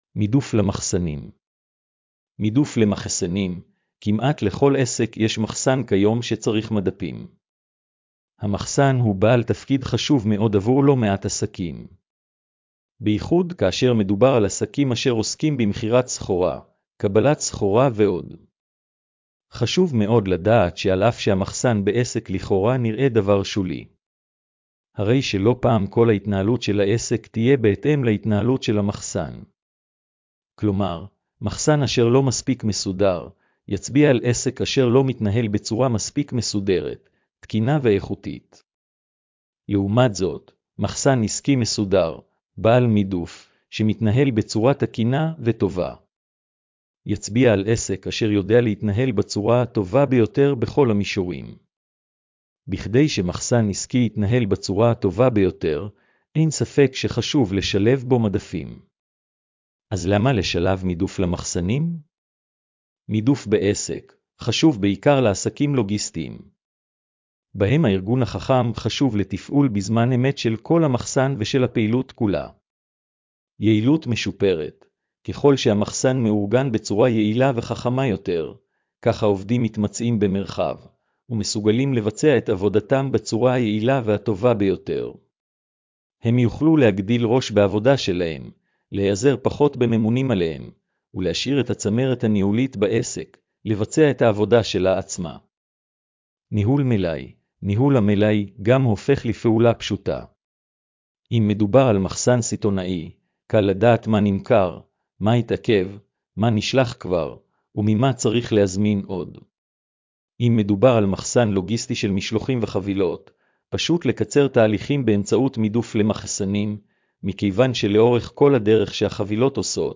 השמעת המאמר לכבדי ראייה: